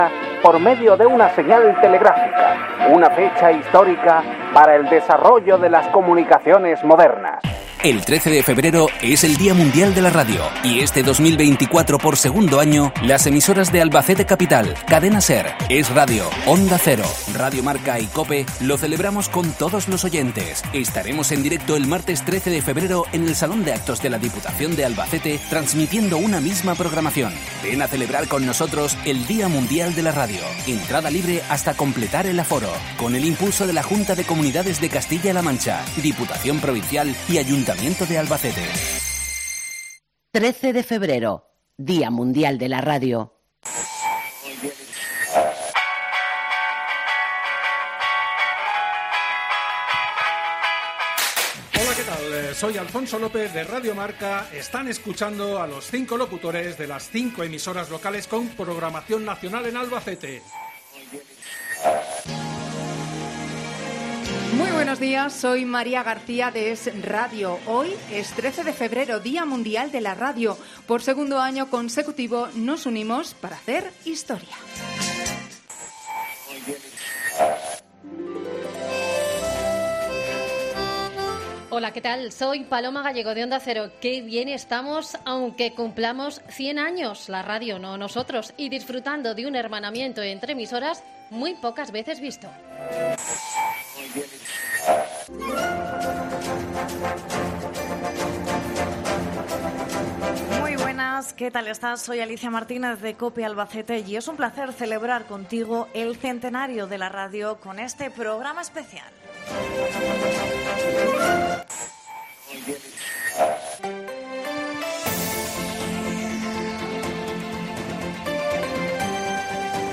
COPE, SER, Onda Cero, EsRadio y Radio MARCA , por segundo año consecutivo se han unido para emitir en directo, desde el salón de actos de la Diputación en el Día de la Radio